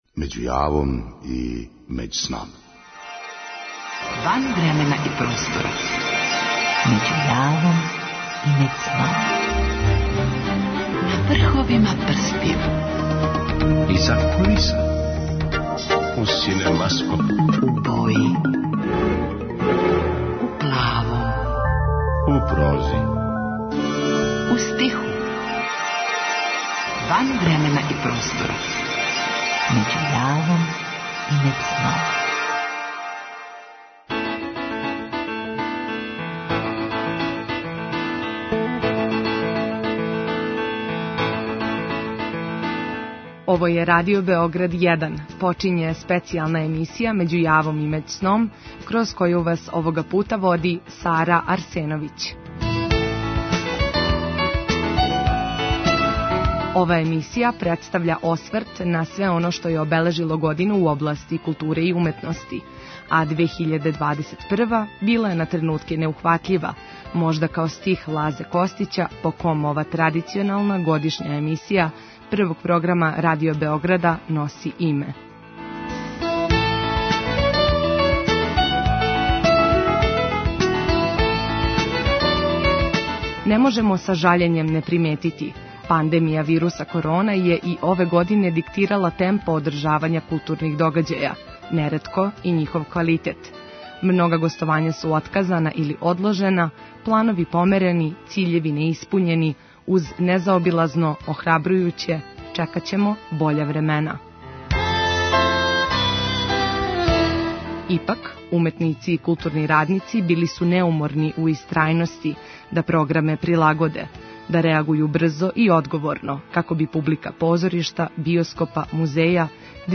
Говорићемо о књигама које су највише читане, новим и старим књижевним наградама, а приредићемо и звучну шетњу кроз наше музеје и галерије.